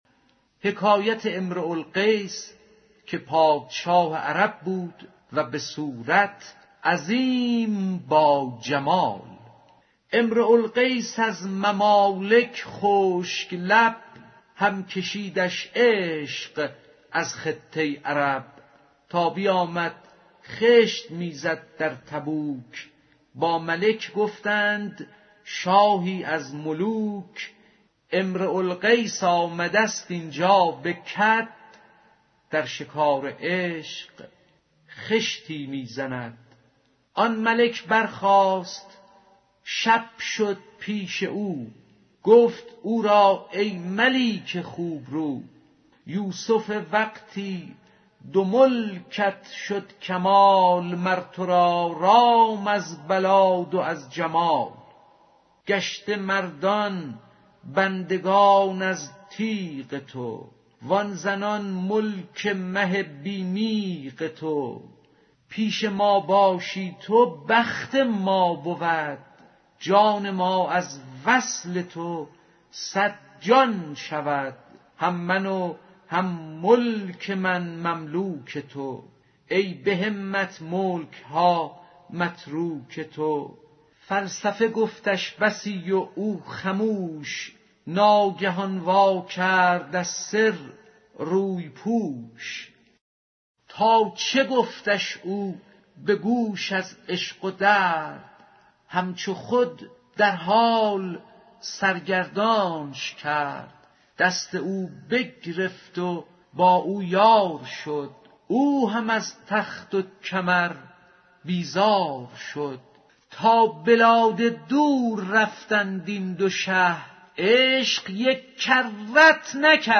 قصه اِمرَوُالقیس که پادشاه عرب بود و بس زیبا روی | دکلمه ، شرح و تفسیر ، دفتر ششم مثنوی معنوی از مولانا جلال الدین محمد بلخی در وب سایت دیدار جان